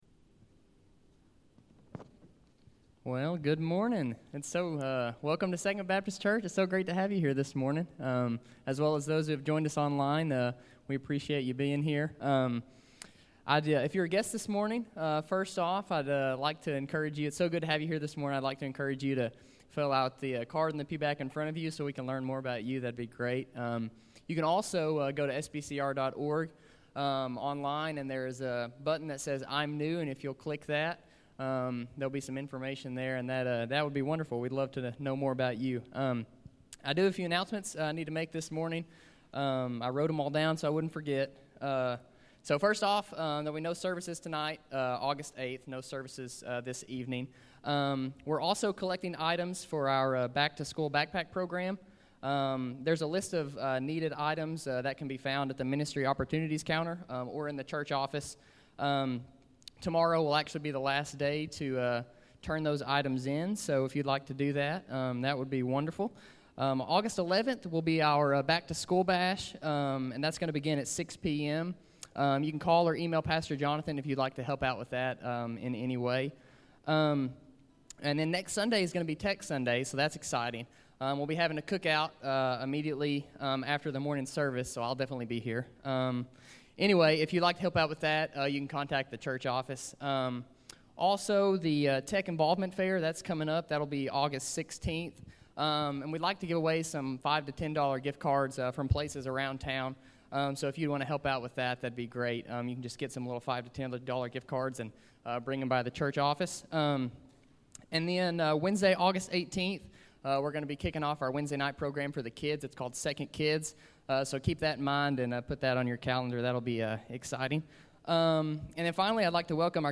Sunday Sermon August 8, 2021